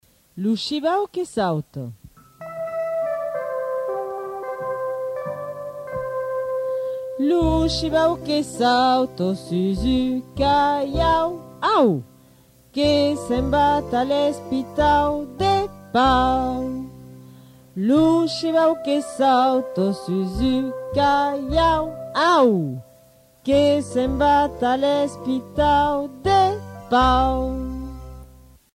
Des Comptines en Béarnais